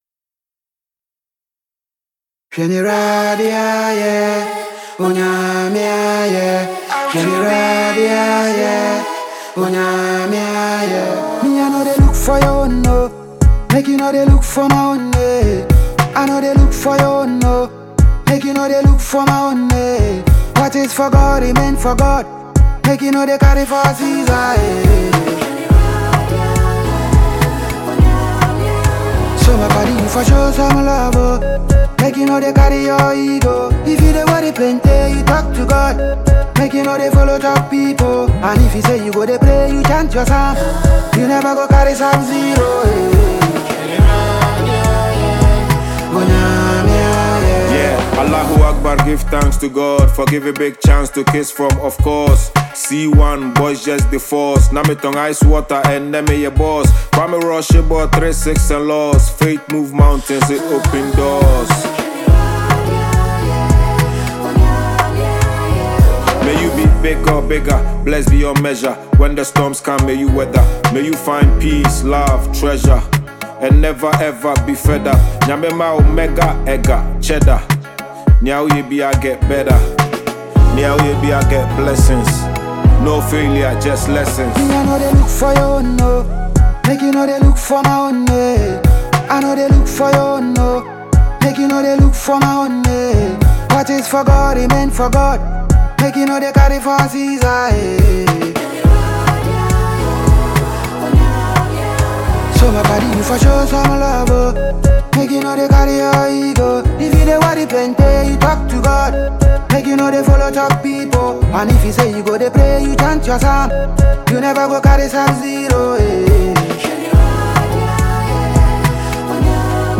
Ghanaian music duo